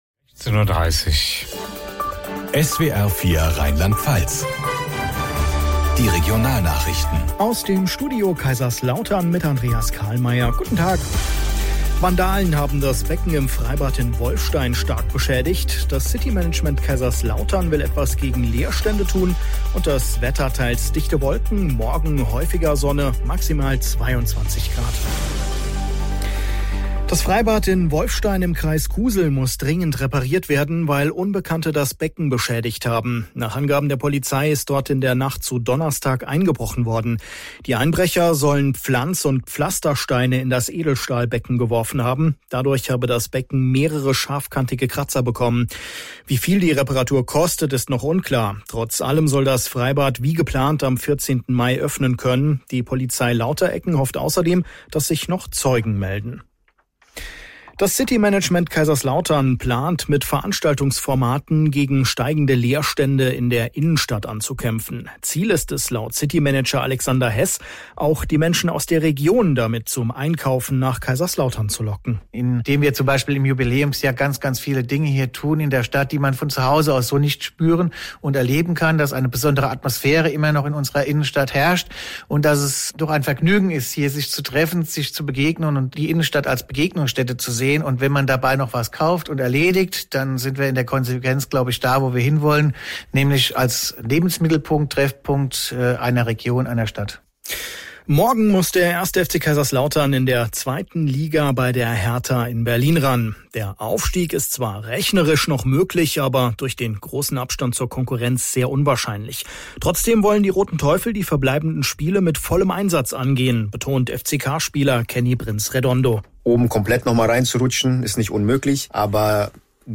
SWR Regionalnachrichten aus Kaiserslautern - 16:30 Uhr